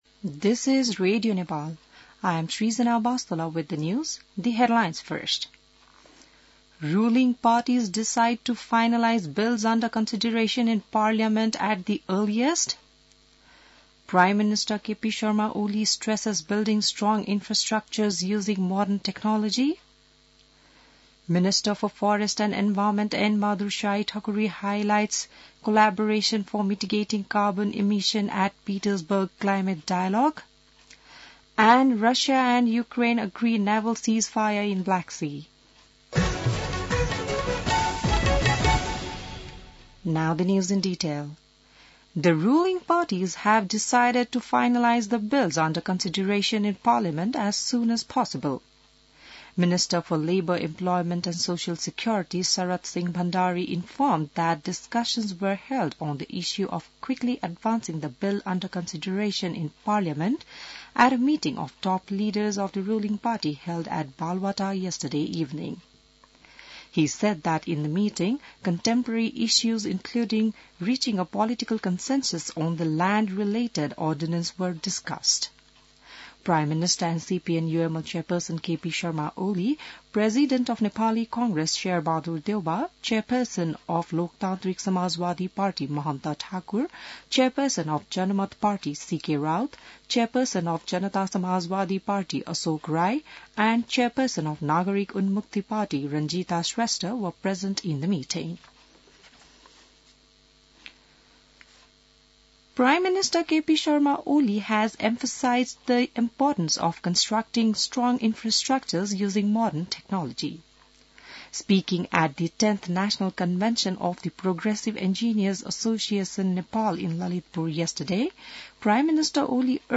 बिहान ८ बजेको अङ्ग्रेजी समाचार : १३ चैत , २०८१